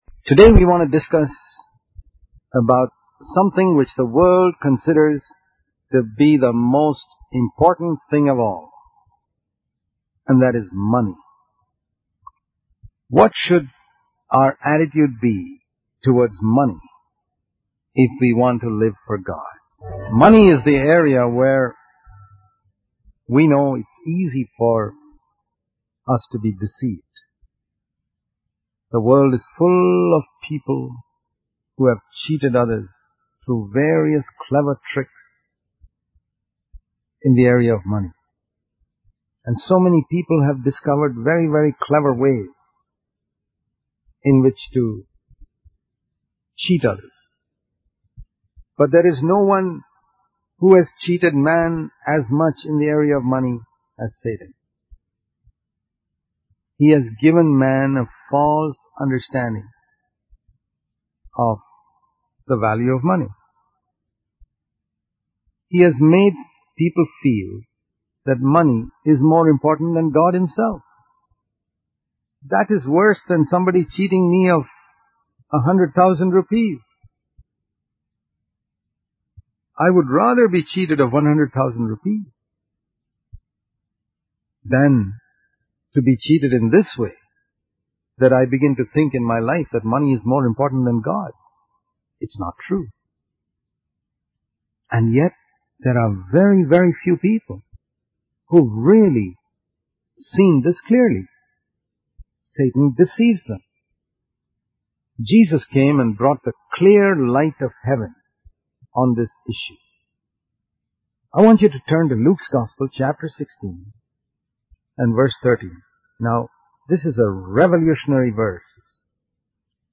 In this sermon, the speaker emphasizes the importance of making a choice between serving God or serving money. He compares money to fire, stating that while it can be a useful servant, it can also be a destructive master if not handled carefully.